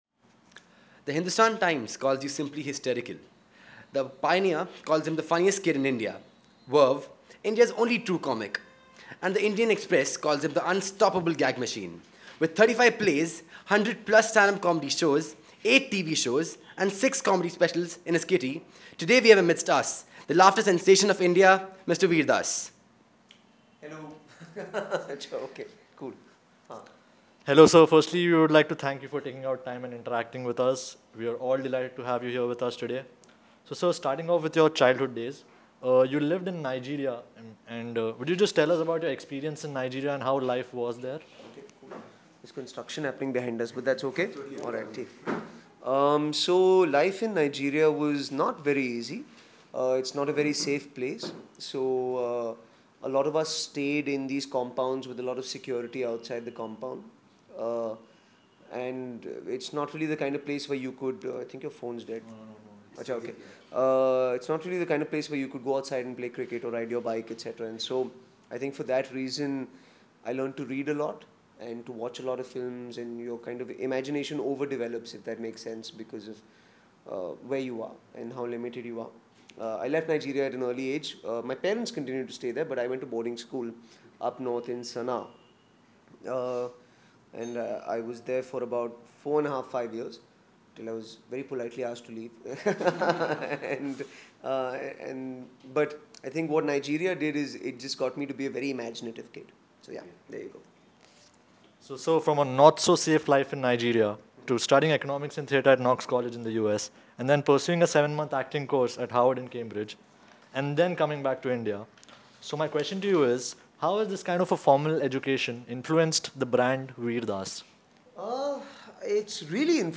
Radio Interview with Vir Das
A radio interview with Mr.Vir Das, the critically acclaimed stand-up comedian, actor, song writer and founder of the production company “WEIRDASS COMEDY”.
vir-das-interview.wav